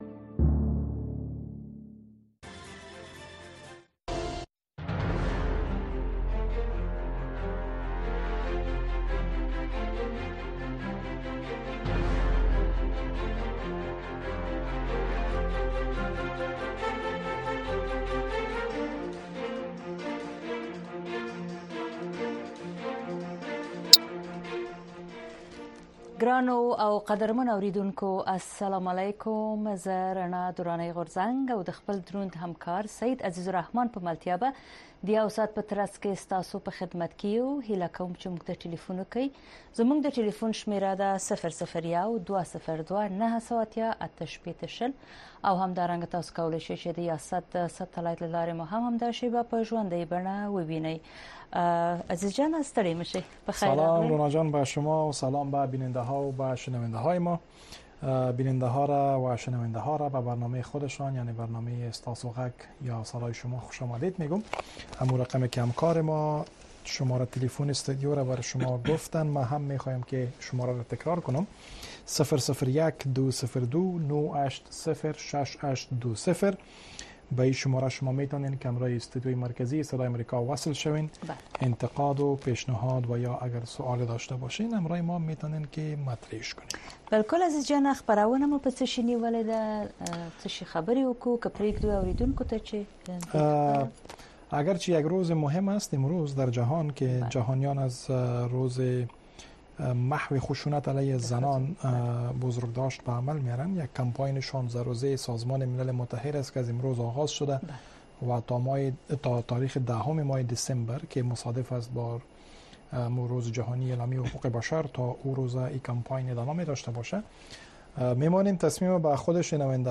در برنامۀ صدای شما، شنوندگان رادیو آشنا صدای امریکا مستقیماً با ما به تماس شده و نگرانی‌ها، دیدگاه‌ها، انتقادات و شکایات شان را با گردانندگان و شنوندگان این برنامه در میان می‌گذارند. این برنامه به گونۀ زنده از ساعت ۹:۳۰ تا ۱۰:۳۰ شب به وقت افغانستان نشر می‌شود.